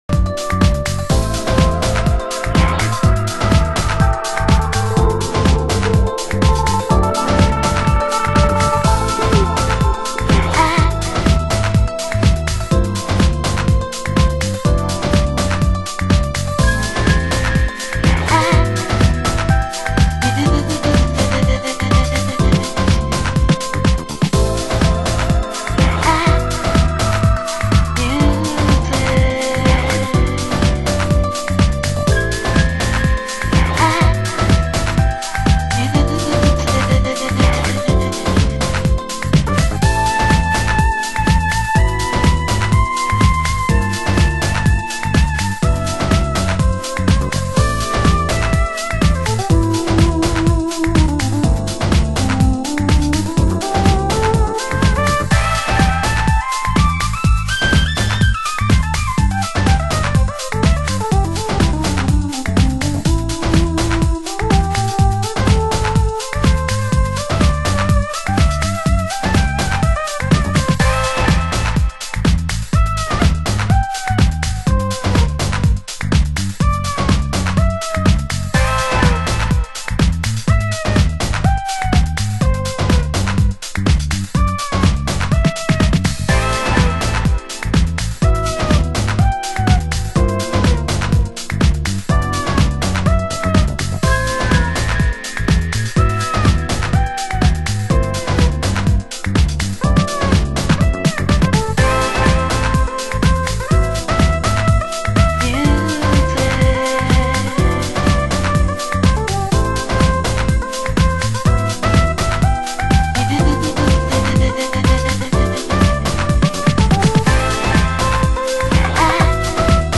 盤質：少しチリパチノイズ有　　ジャケ：薄汚れ/部分的に黄ばみ有